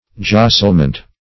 Jostlement \Jos"tle*ment\, n.